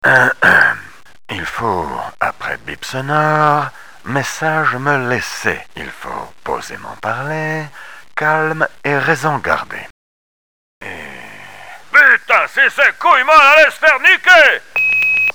Messages d'imitations 1:
Chirac enerve.mp3